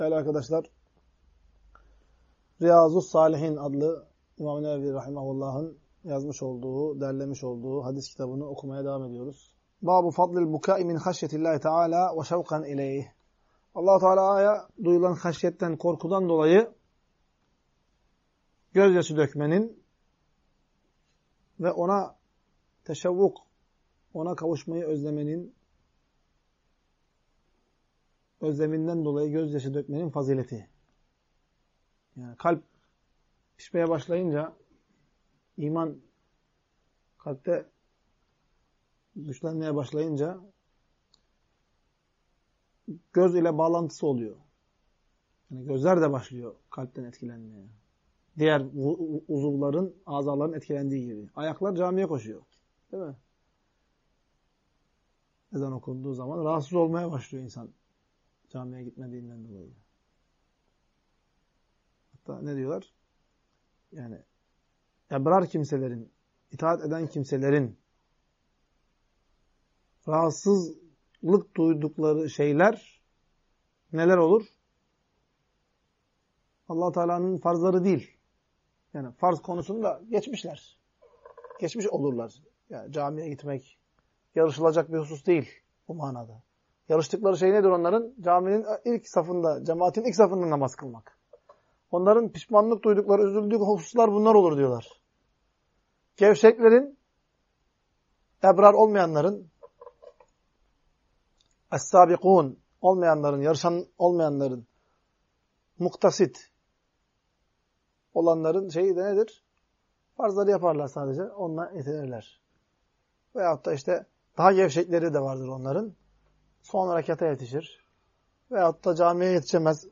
Ders.mp3